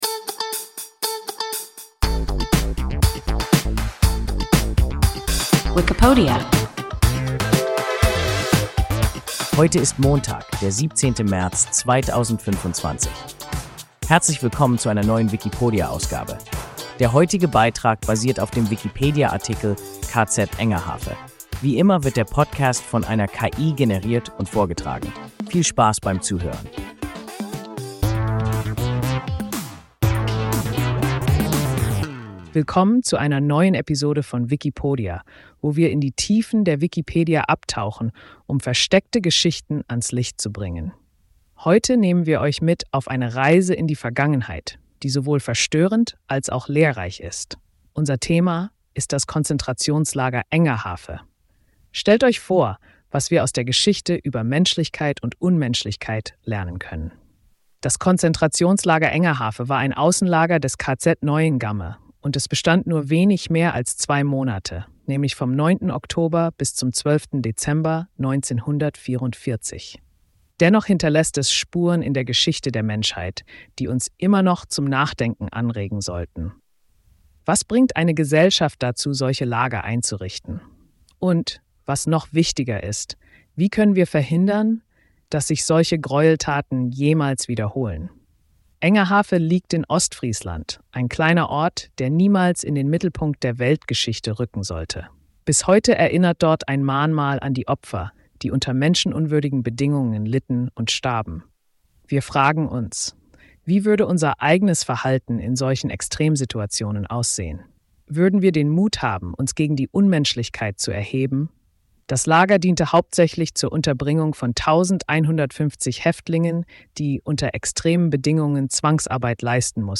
KZ Engerhafe – WIKIPODIA – ein KI Podcast